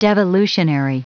Prononciation du mot devolutionary en anglais (fichier audio)
Prononciation du mot : devolutionary